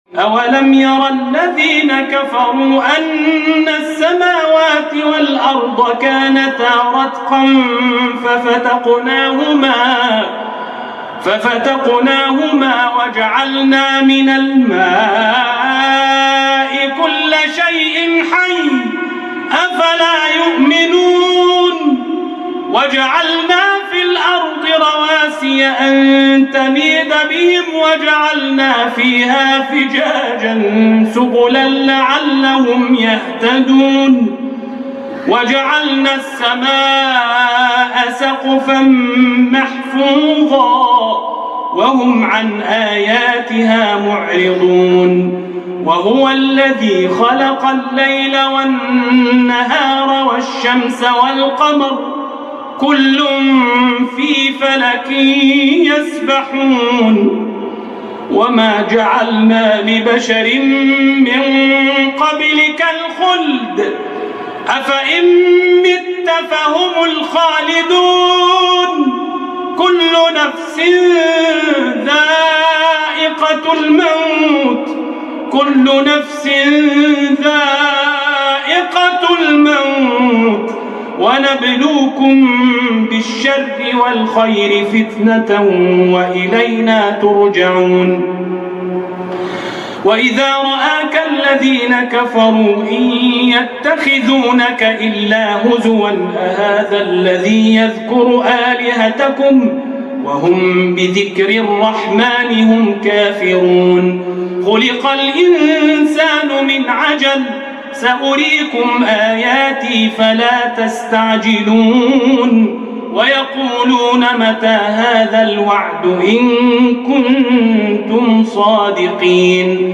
May God make us meet your good expectations - El Forqaan For Recitations and Quran Science